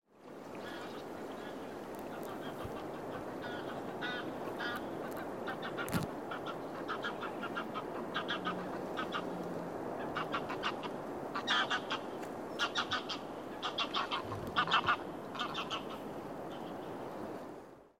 09 树叶上的脚步声；更快的步伐；开放的空间；接近；自然
描述：更快的节奏;开放空间;近;性质;鸟类;脚步声;离开田间记录森林环境
Tag: 场记录 鸟类 开放 环境 森林近 起搏 空间 叶子 脚步 性质 更快